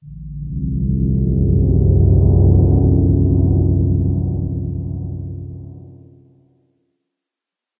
minecraft / sounds / ambient / cave
cave3.ogg